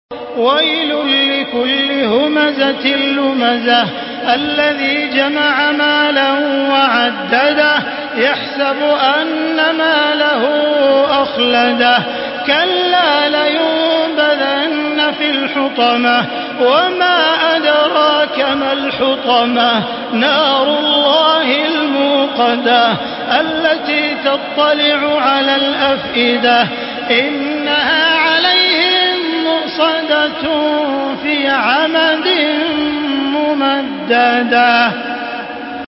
Surah Humeze MP3 by Makkah Taraweeh 1435 in Hafs An Asim narration.
Murattal